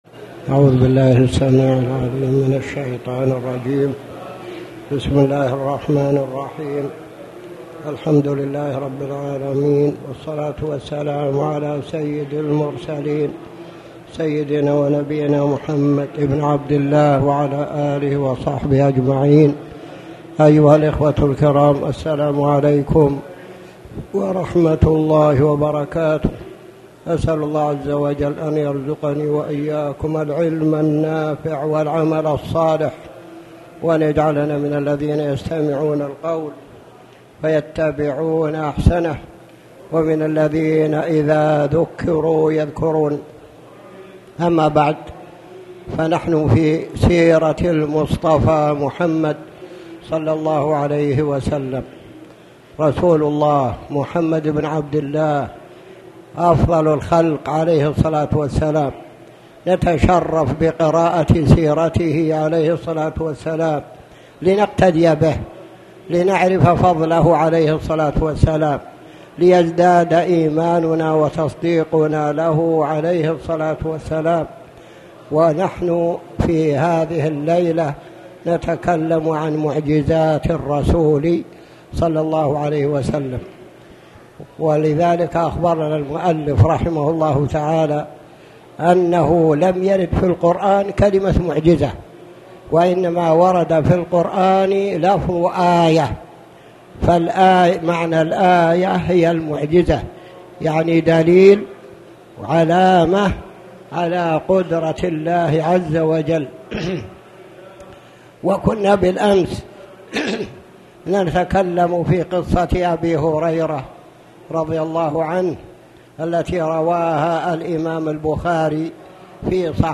تاريخ النشر ٤ محرم ١٤٣٩ هـ المكان: المسجد الحرام الشيخ